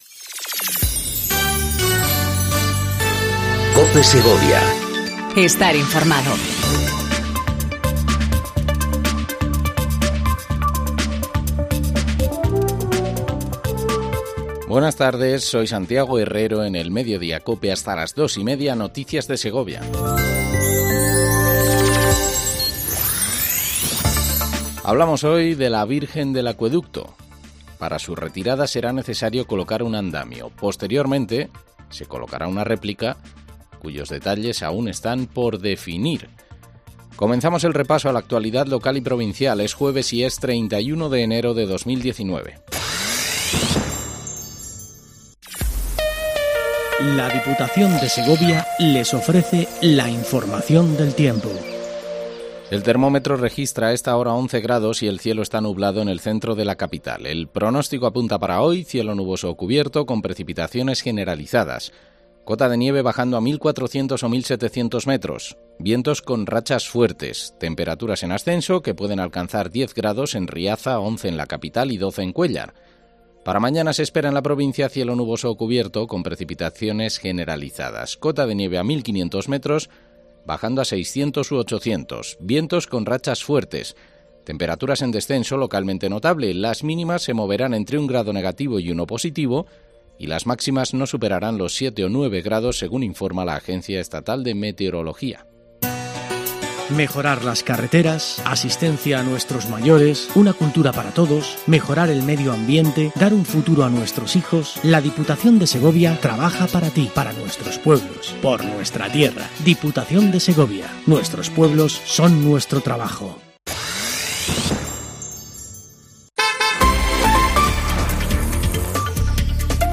INFORMATIVO DEL MEDIODÍA EN COPE SEGOVIA 14:20 DEL 31/01/19